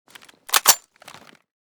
vz61_unjam.ogg